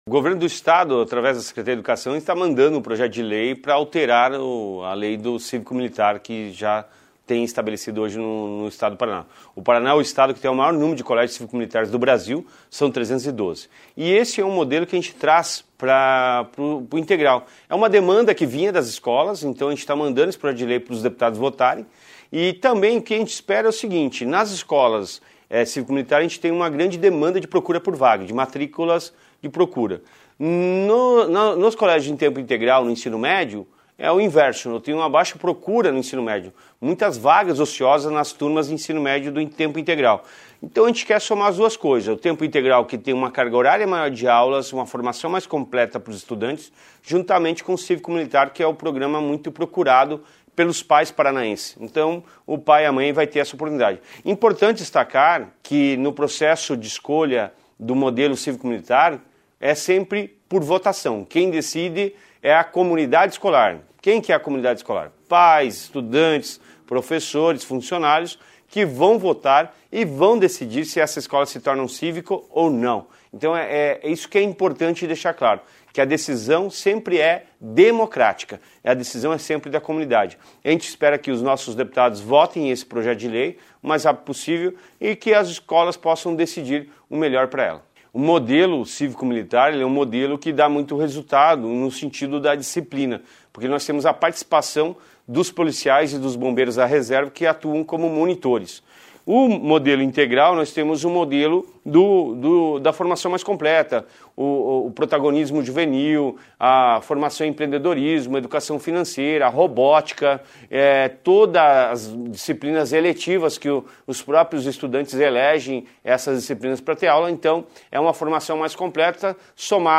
Sonora do secretário Estadual da Educação, Roni Miranda, sobre o projeto de Lei que propõe colégios cívico-militares na educação integral